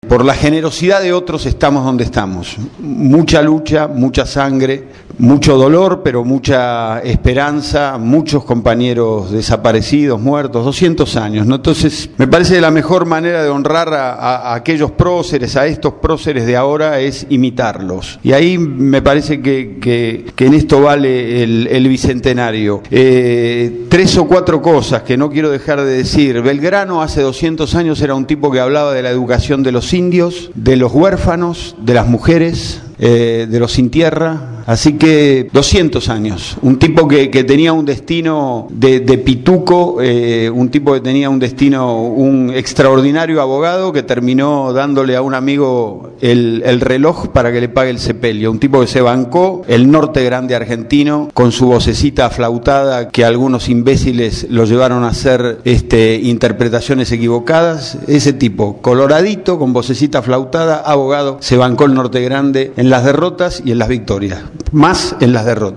Organizado por Radio Gráfica y el programa «Cambio y Futuro», el último 19 de Mayo se realizó en la instalaciones de la radio la charla debate «Educación en el Bicentenario«.
Ante un centenar de presentes, la expectativa era mucha porque el panel de invitados así lo proponía: